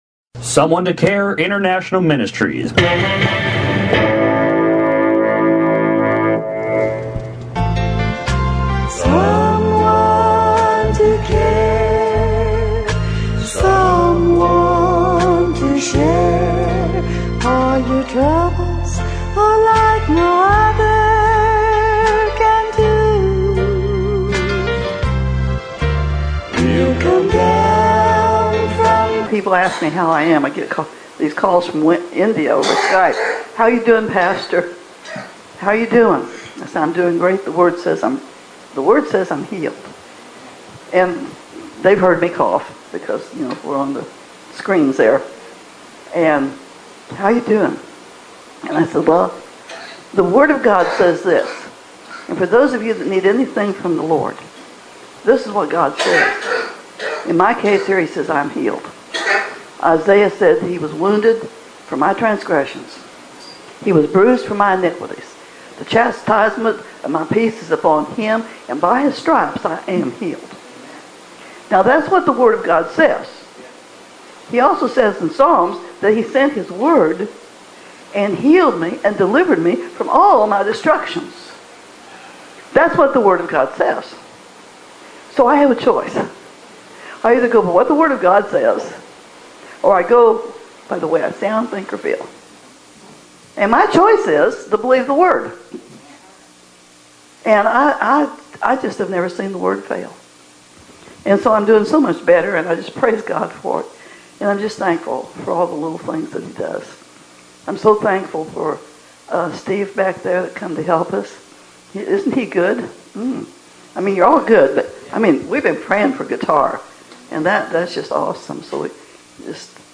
MP3 SERMONS